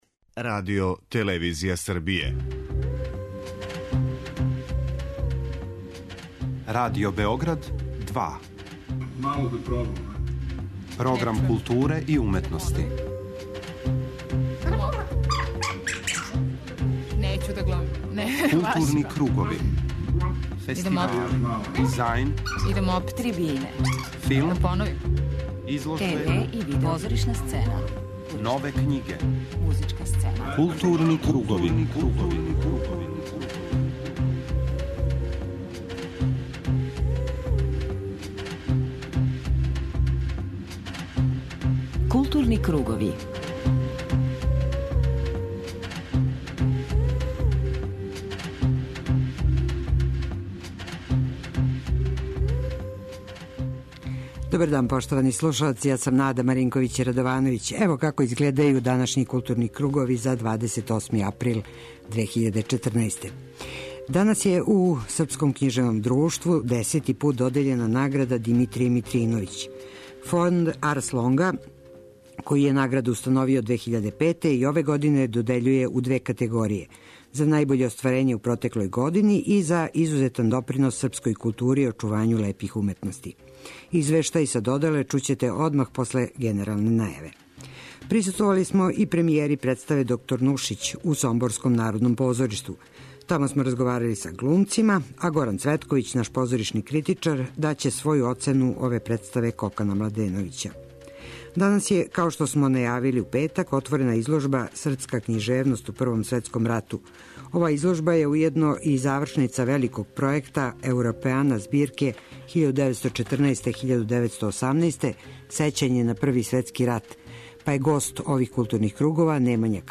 Тематски блок Арс сонора обележиће концерти наших познатих пијаниста, Александра Маџара, који је наступио са Симфонијским оркестром РТС под управом Бојана Суђића, и Кемала Гекића.
преузми : 54.07 MB Културни кругови Autor: Група аутора Централна културно-уметничка емисија Радио Београда 2.